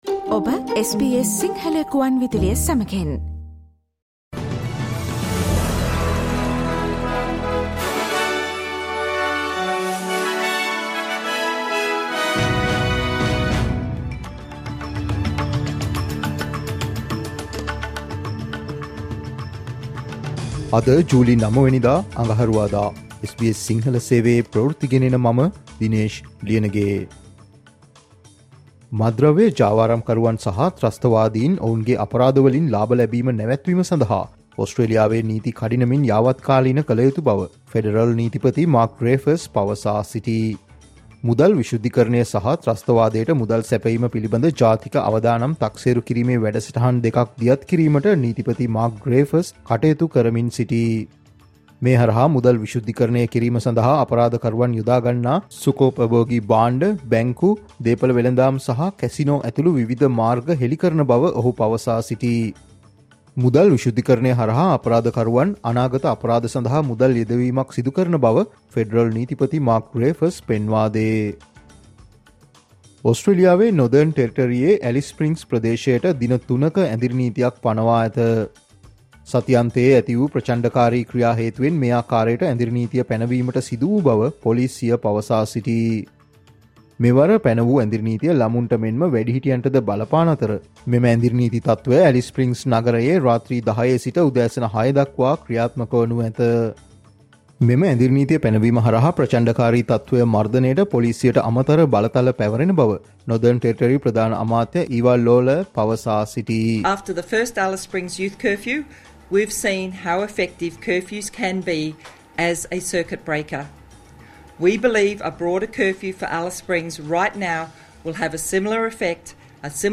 Australia's news in Sinhala.